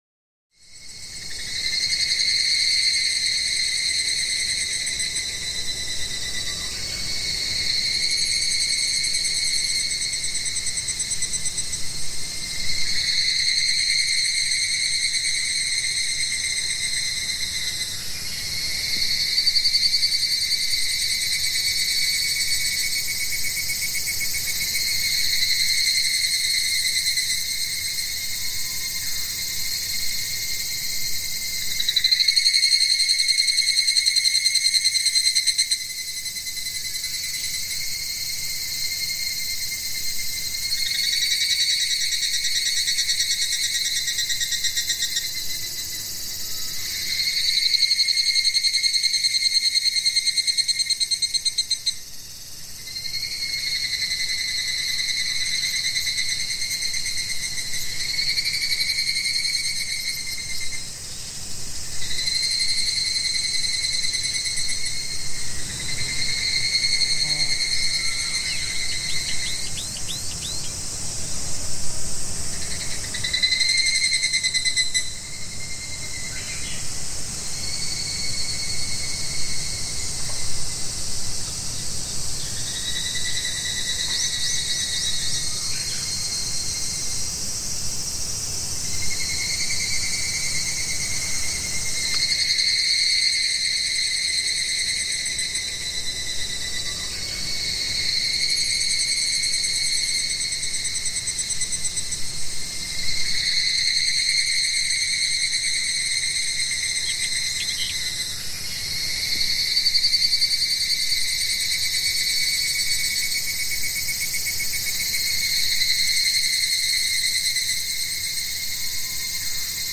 Sommergeräusche Zikaden & Natur-ASMR | Schlaf & Lernen